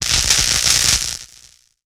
Electric Crackle.wav